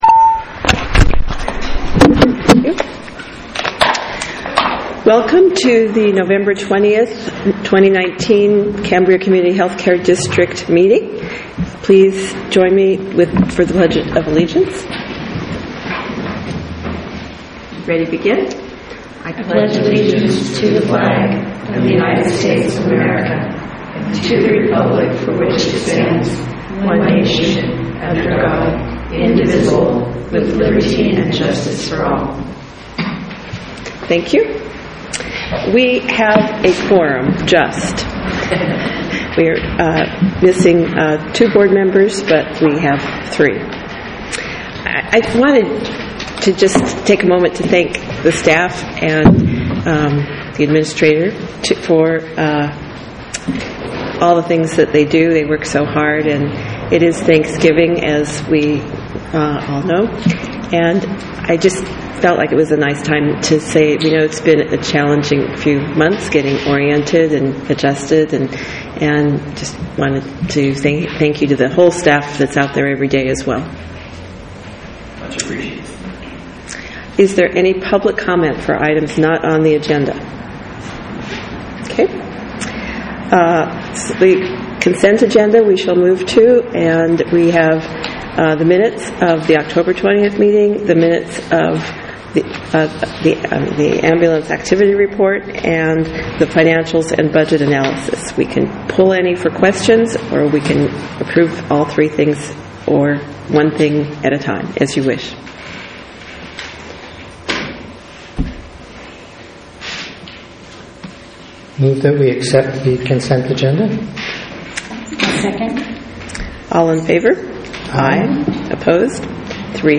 REGULAR BOARD MEETING